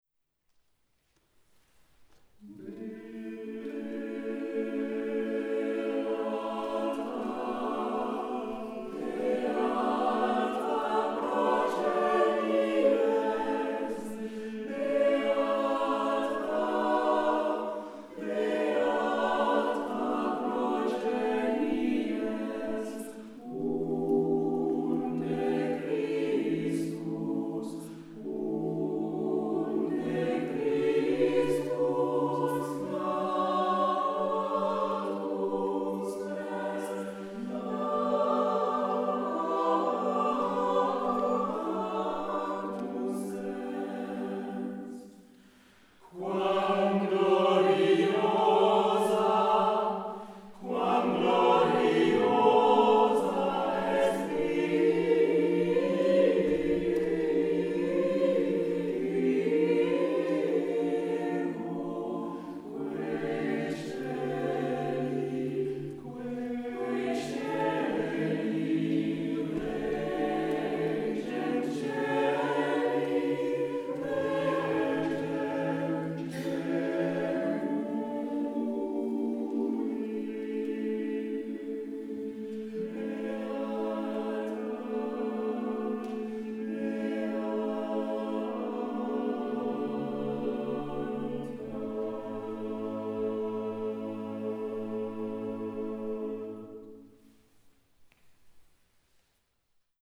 SATB + soli + 2 trompettes et orgue ou ensemble instrumental
1ère version : chœur, 2 trompettes en ut et grand orgue.